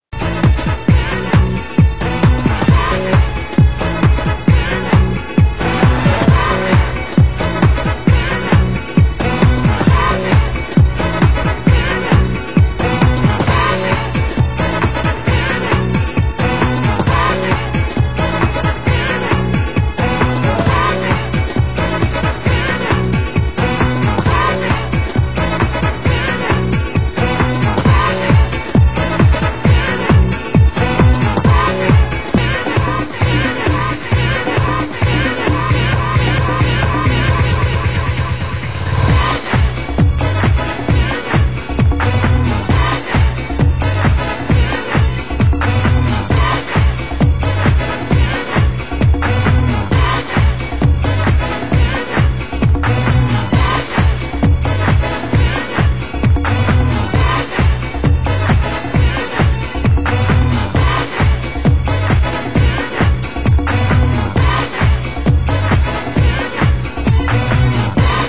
Genre: UK Garage